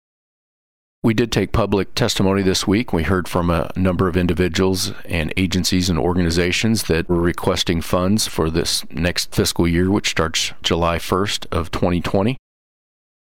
4. Senator Hoskins says Missouri’s Fiscal Year 2021 operating budget is due on the governor’s desk on May 8.